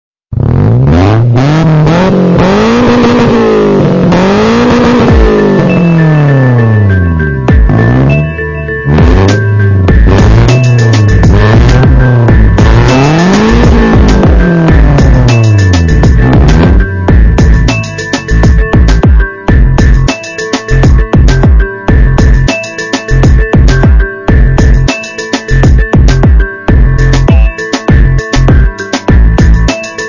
Рингтоны » 3d звуки » Спортивный звук выхлопа двигателя